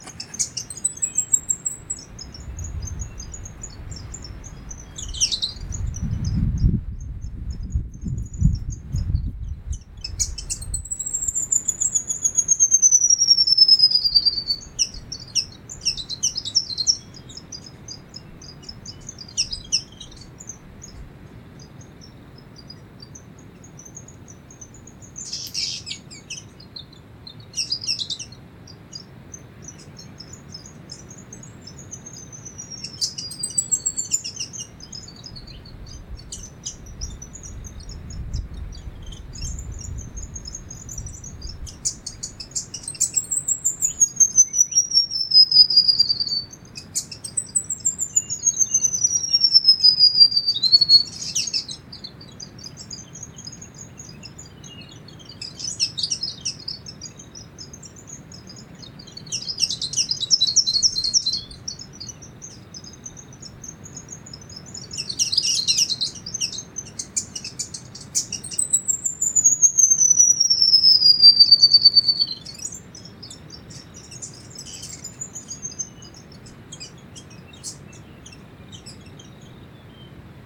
Grey Fantail
Rhipidura albiscapa pelzelni
Fantail Grey (pelzelni) MT BATES NF AUS pair song [A] ETSJ_LS_71920 (edit).mp3